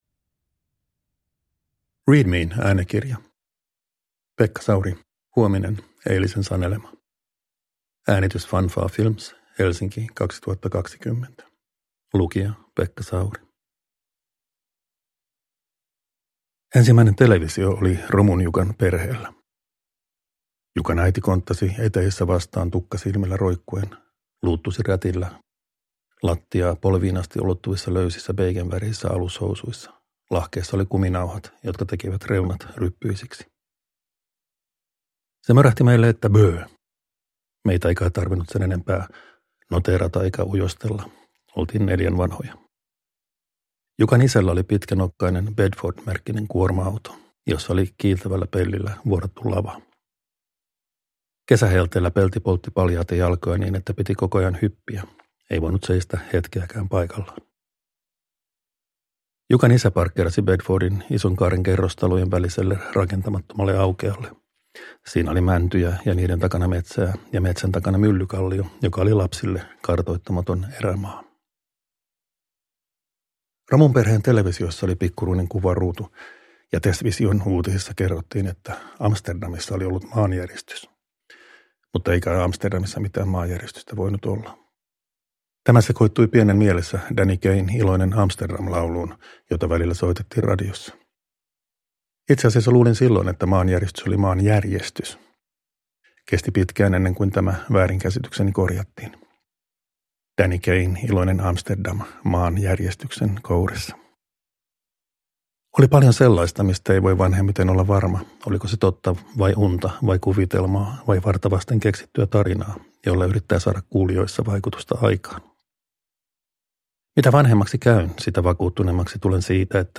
Huominen, eilisen sanelema – Ljudbok
Uppläsare: Pekka Sauri